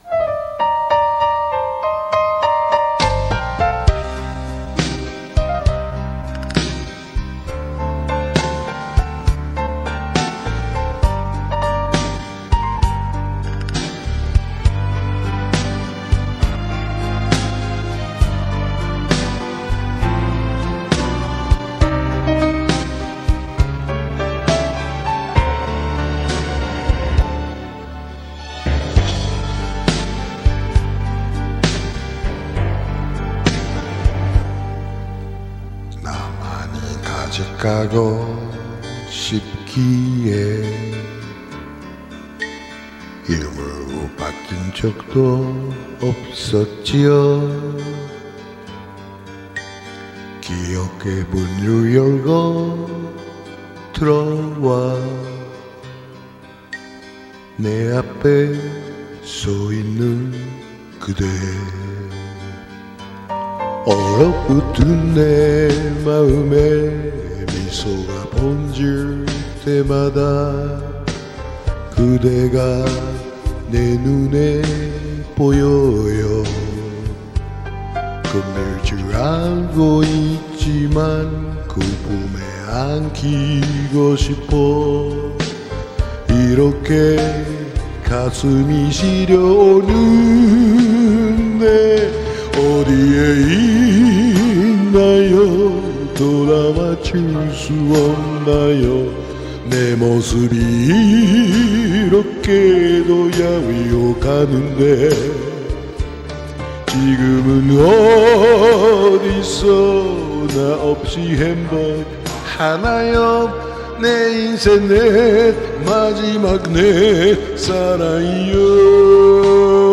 Korean Karaoke